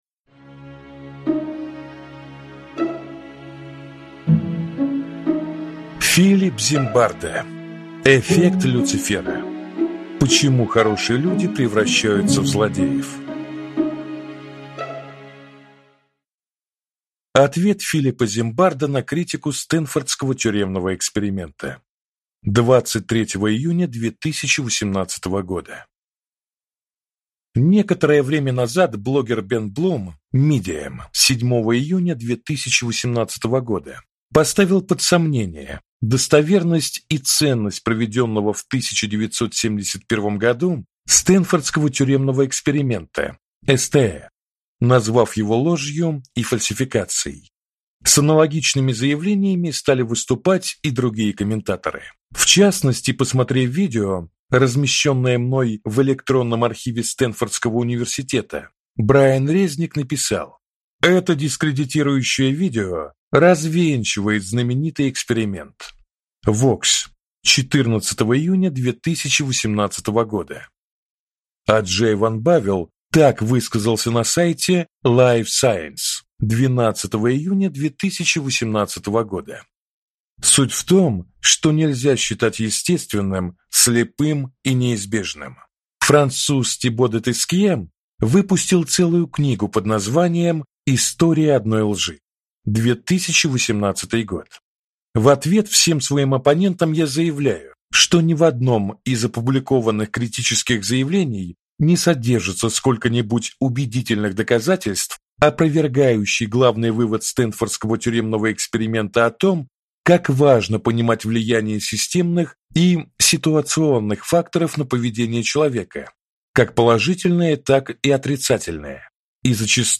Аудиокнига Эффект Люцифера. Почему хорошие люди превращаются в злодеев | Библиотека аудиокниг